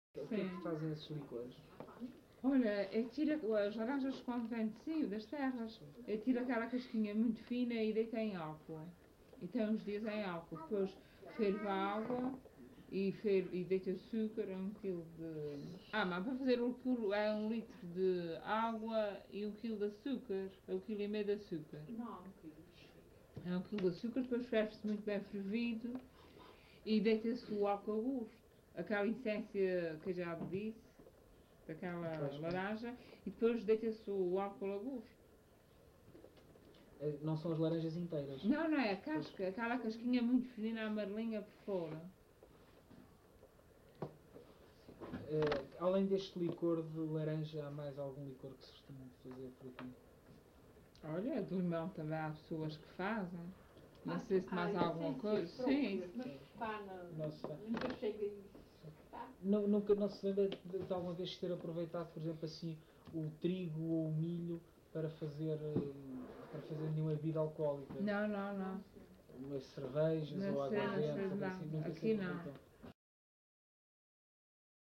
LocalidadeVila do Corvo (Corvo, Horta)